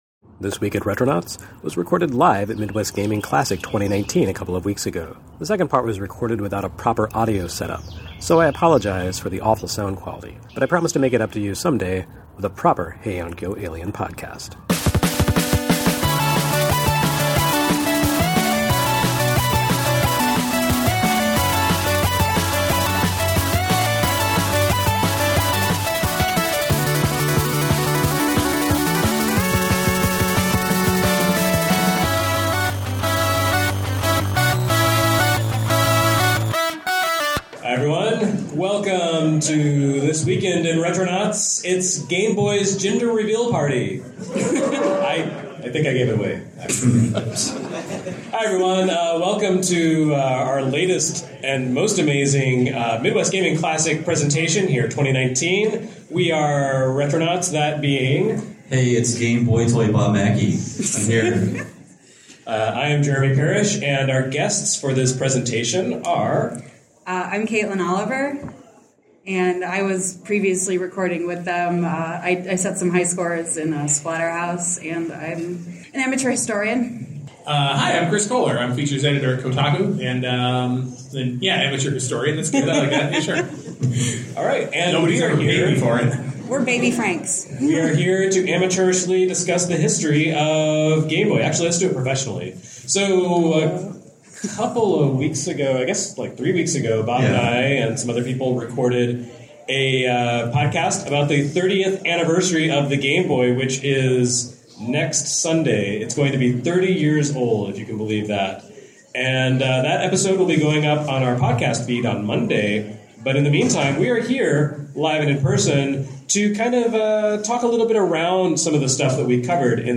Retronauts Episode 217: Game Boy at Midwest Gaming Classic
Live!